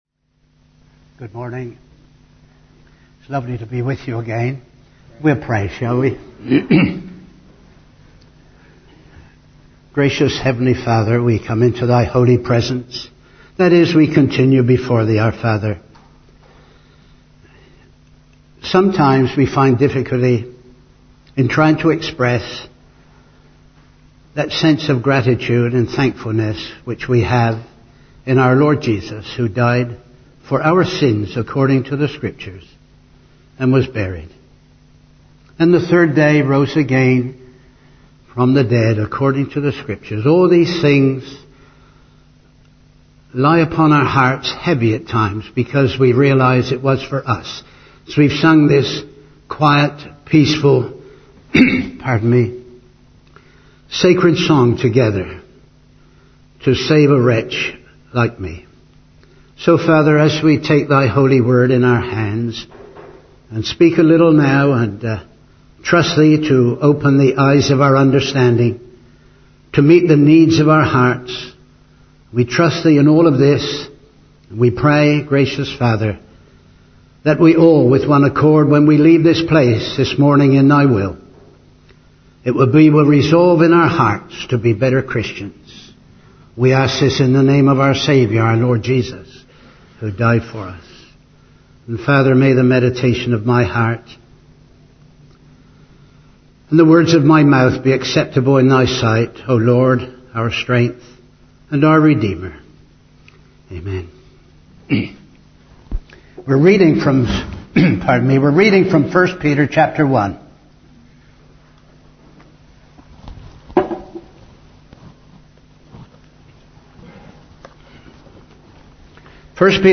2 Peter 3:18 Service Type: Family Service Topics